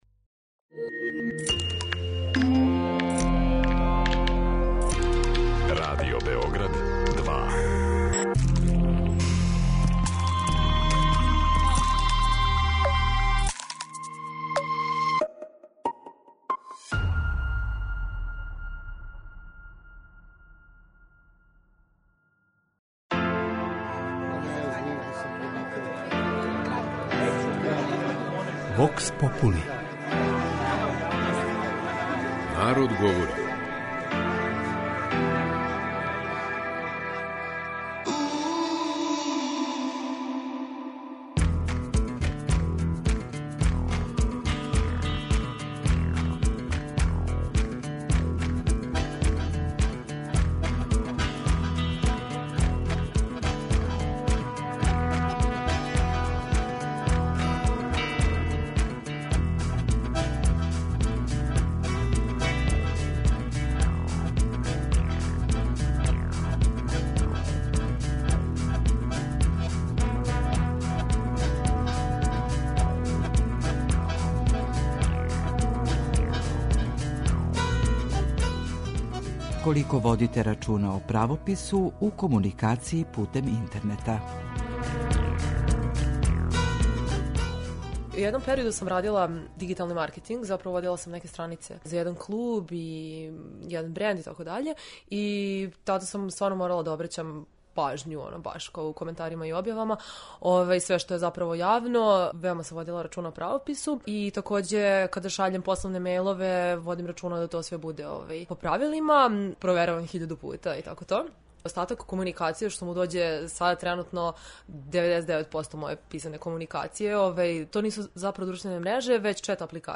кроз кратке монологе, анкете и говорне сегменте